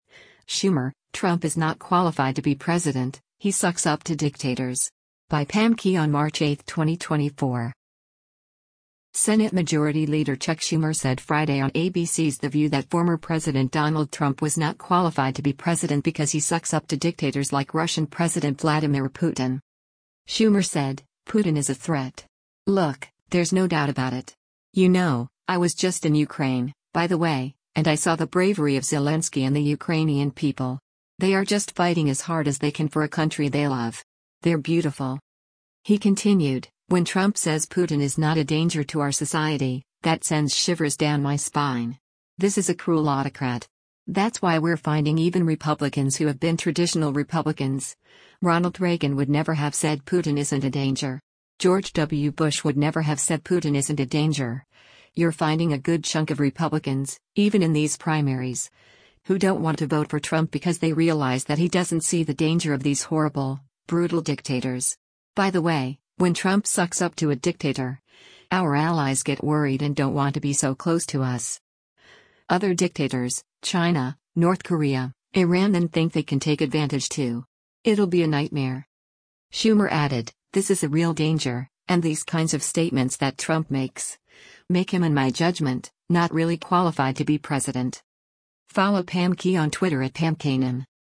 Senate Majority Leader Chuck Schumer said Friday on ABC’s “The View” that former President Donald Trump was not qualified to be president because he “sucks up” to dictators like Russian President Vladimir Putin.